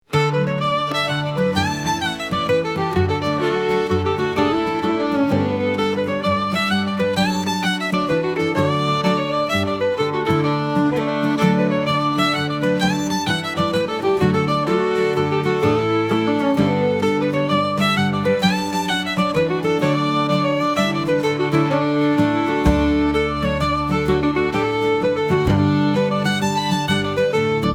This is the type of music you would hear in medieval times.